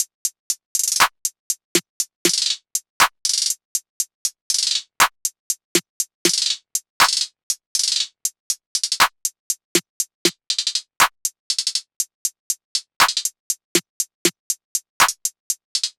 SOUTHSIDE_beat_loop_swiss_top_120.wav